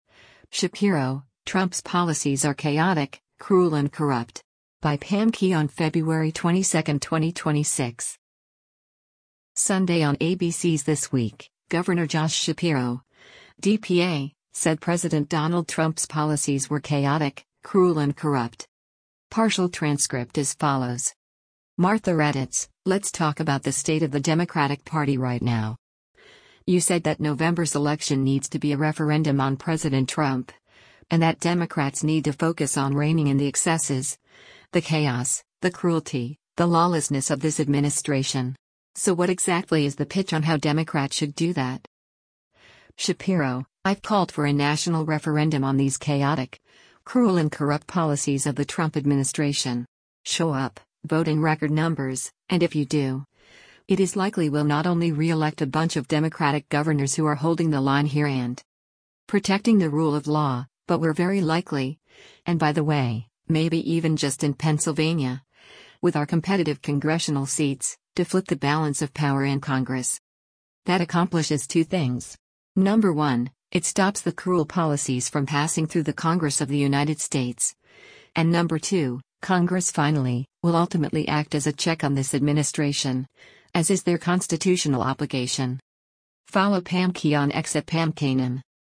Sunday on ABC’s “This Week,” Gov. Josh Shapiro (D-PA) said President Donald Trump’s policies were “chaotic, cruel and corrupt.”